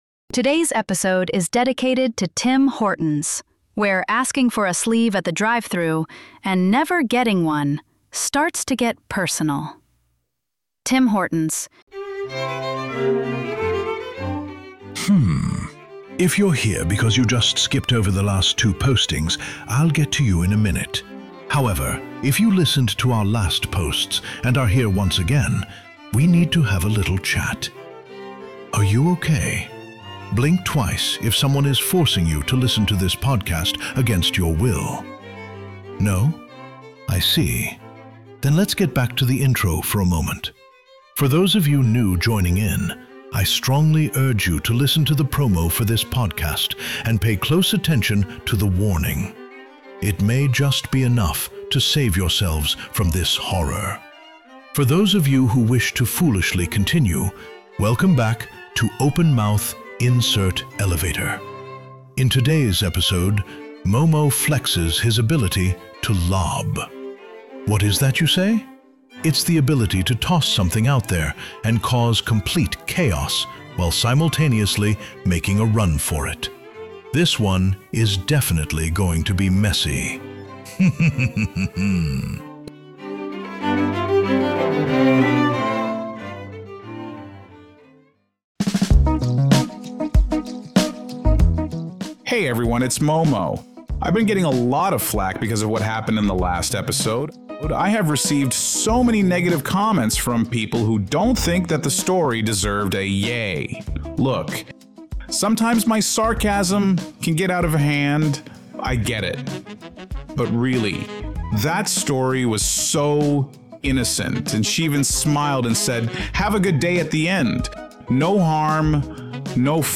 They also recount lighter moments from work settings and childhood adventures. Throughout the episode, they sprinkle moments of spontaneous laughter, periodic feedback from listeners, and reflections on what they’ve learned from their mishaps.